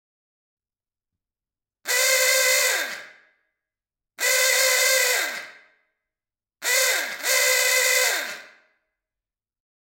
Fisa luchthoorn 12V Epoque - FQM | 146105
Fisa luchthoorn 12V Epoque - FQM115dB72W350Hz